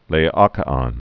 (lā-ŏkə-ŏn)